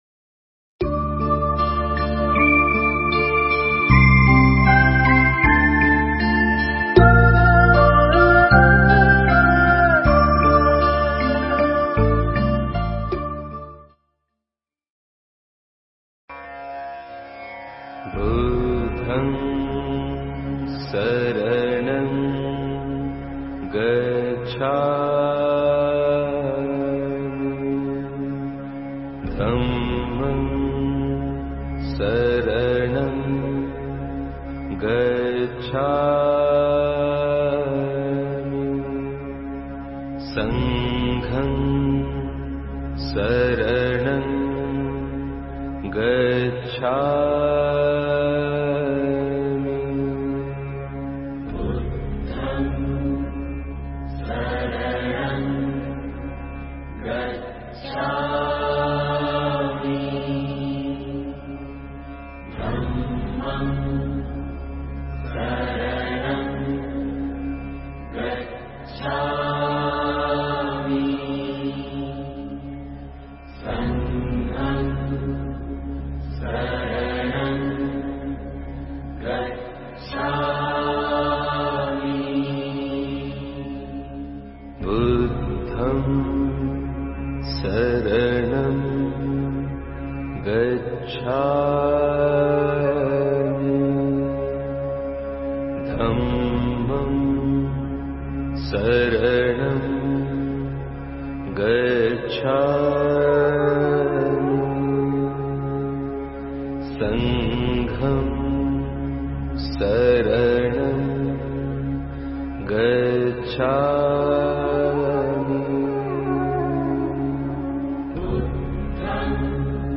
Nghe Mp3 thuyết pháp Kinh Trung Bộ